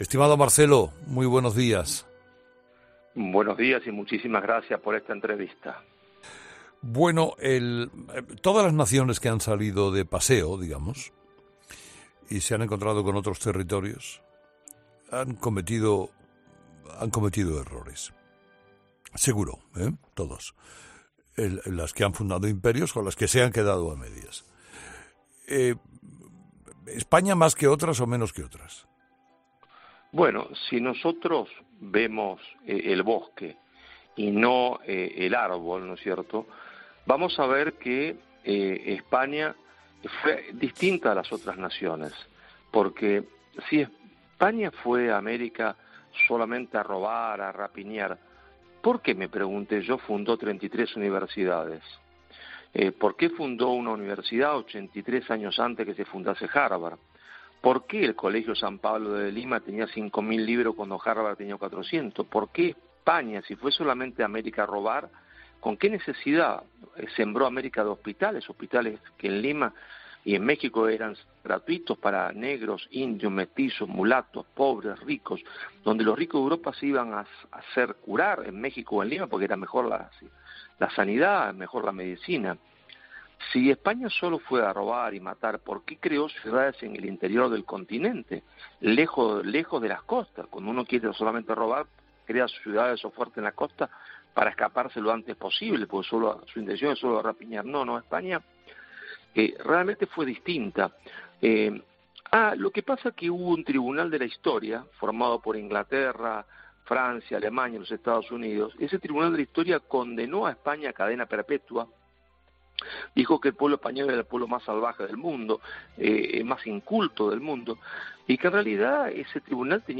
Carlos Herrera ha querido comenzar su entrevista al escritor y politólogo Marcelo Gullo con una pregunta: "Todas las naciones que han salido de paseo, digamos y se han encontrado con otros territorios han cometido errores, seguro, todos los que han fundado imperios o los que se han quedado a medias. ¿España más que otras o menos que otras?".